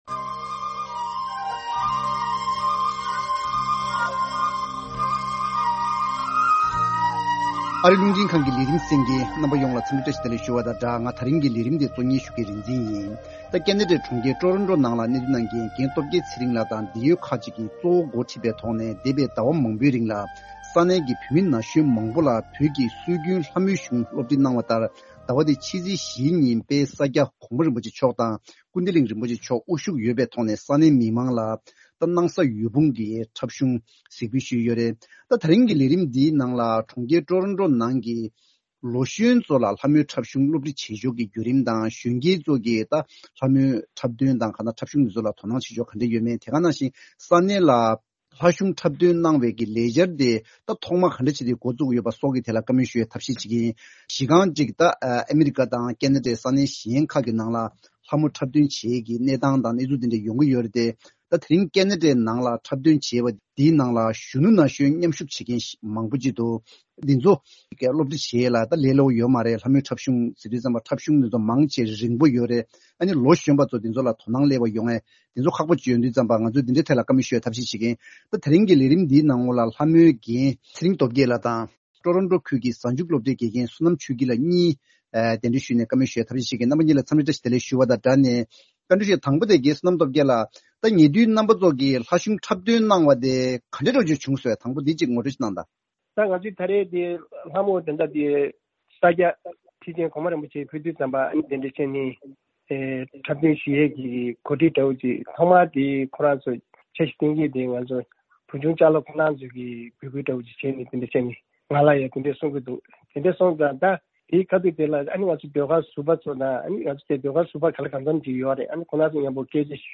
Table Talk: Youth Participation, Interest, and Lessons Learnt from Opera (Lhamo) Performance.